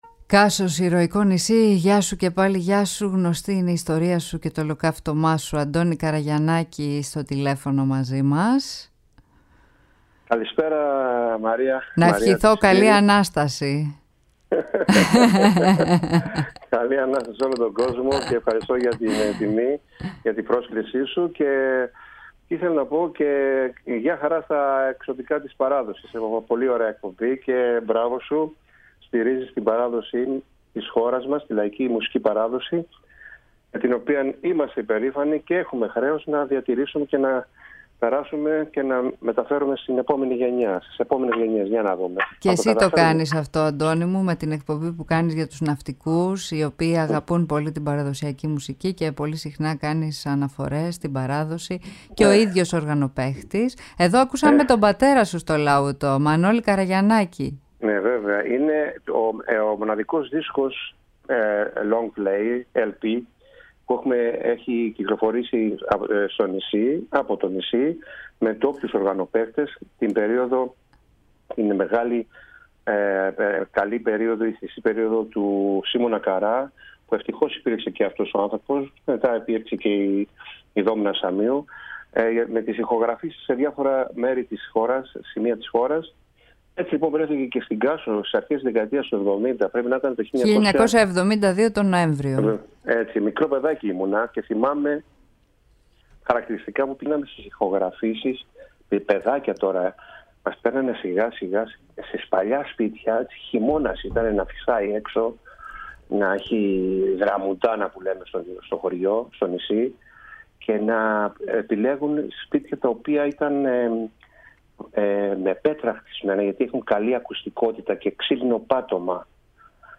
μίλησε στην εκπομπή τα “Ξωτικά της Παράδοσης”